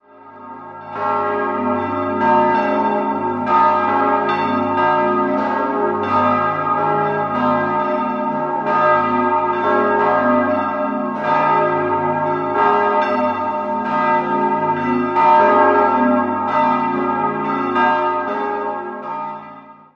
3-stimmiges Geläute: gis°-dis'-e''